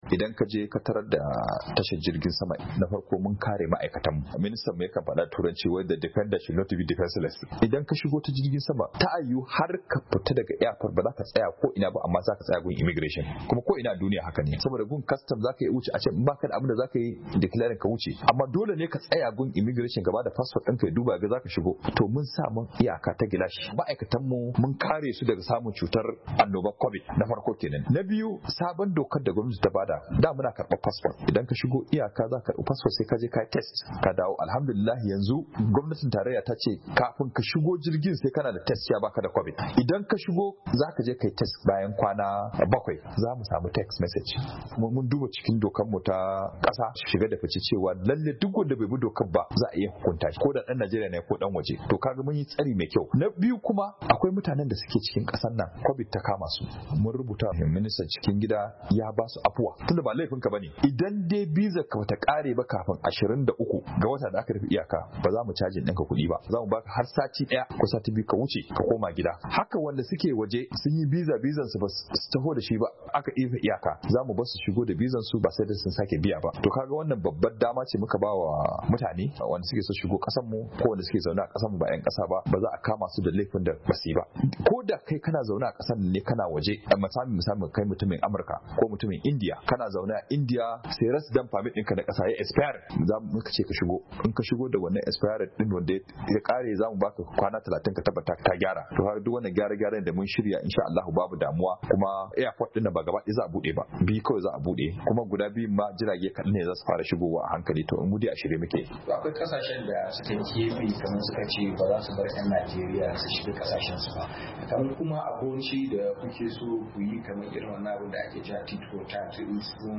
HIRA DA SHUGABAN MIGIRESHIN NAJERIYA A KAN BUDE FILAYEN JIRAGEN SAMA